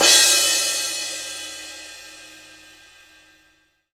Index of /90_sSampleCDs/Sound & Vision - Gigapack I CD 1 (Roland)/CYM_CRASH mono/CYM_Crash mono
CYM CRA02.wav